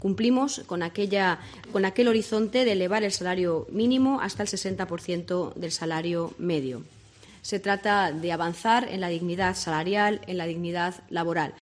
El Gobierno aprueba la subida del SMI a 1.080 euros como han confirmado en la rueda de prensa